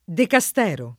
decastero [ deka S t $ ro ]